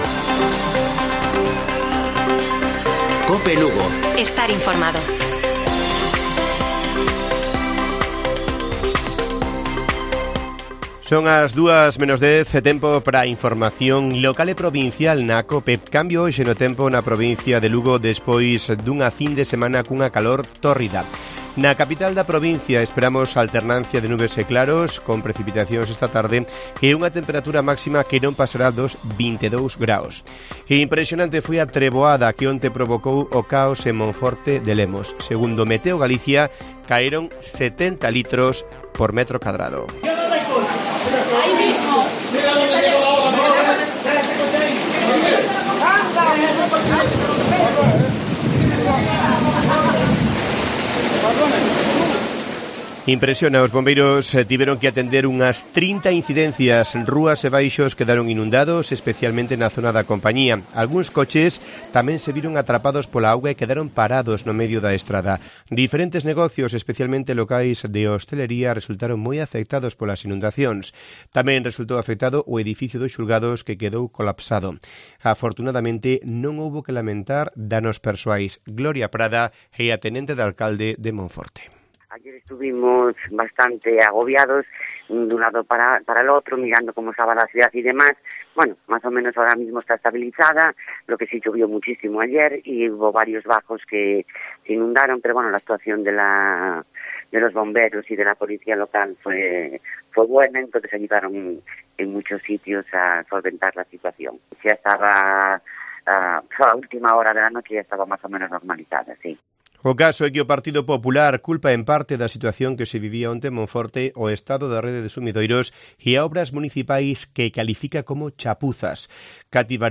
Informativo Mediodía de Cope Lugo. 30 DE MAYO. 13:50 horas